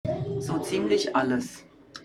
MS Wissenschaft @ Diverse Häfen
Standort war das Wechselnde Häfen in Deutschland. Der Anlass war MS Wissenschaft